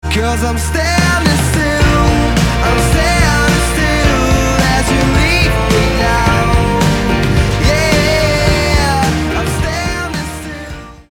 GenrePop